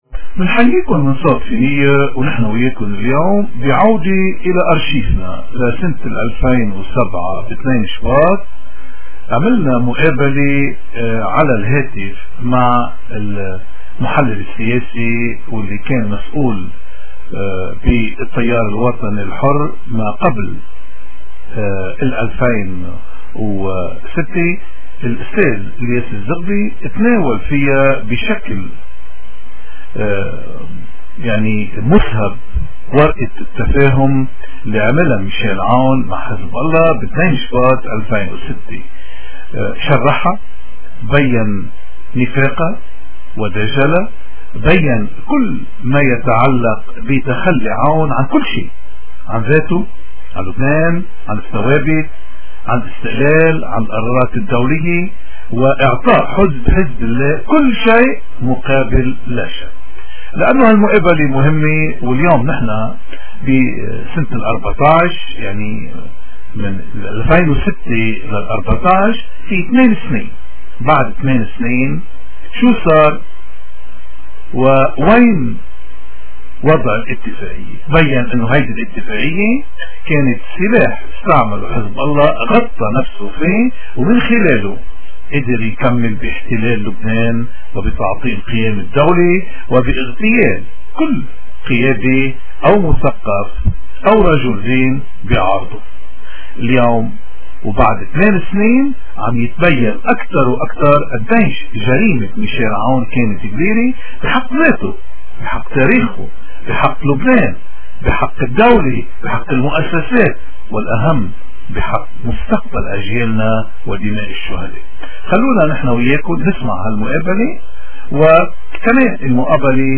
نشر مقابلة بالصوت والنص